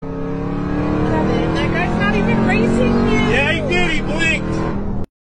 Car Speeding Up Efeito Sonoro: Soundboard Botão
Car Speeding Up Botão de Som